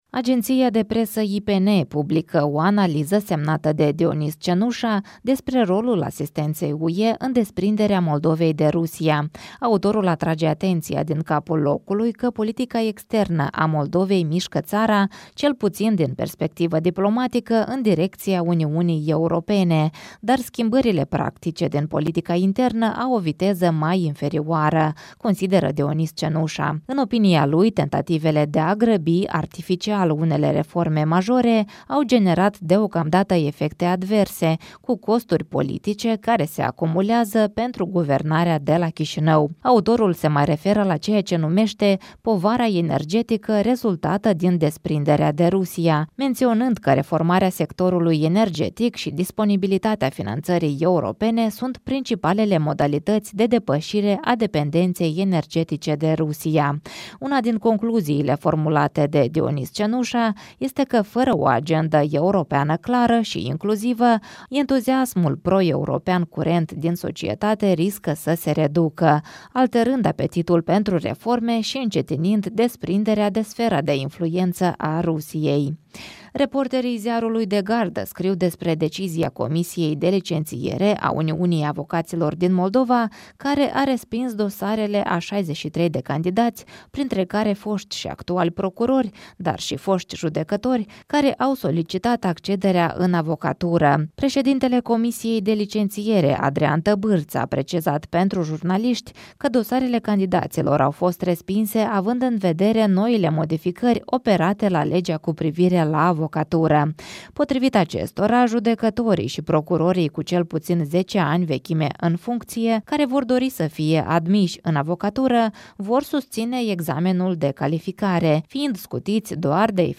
Revista matinală a presei de la Chișinău